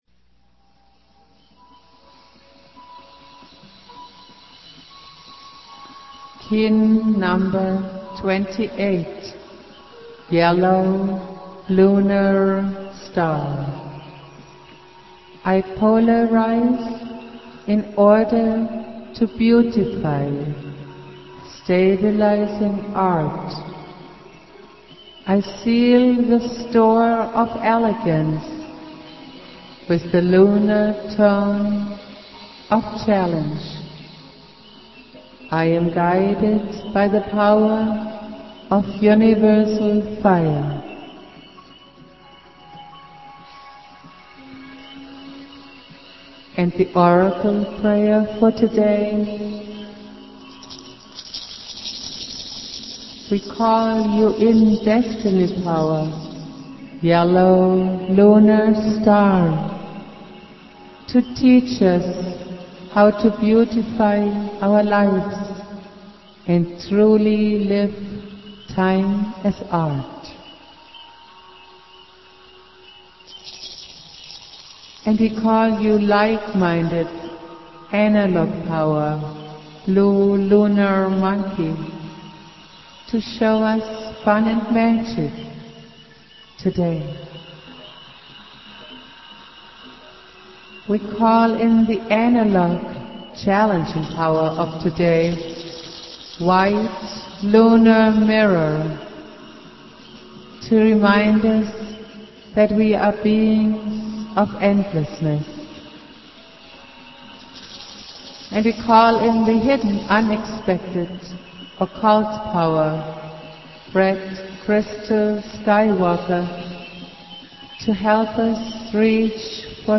Prayer
Jose Argüelles playing flute